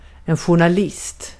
Uttal
Synonymer tidningsman publicist ajourförare Uttal Okänd accent: IPA: /ɧʊrnaˈlɪst/ Ordet hittades på dessa språk: svenska Översättning 1. gazeteci Artikel: en .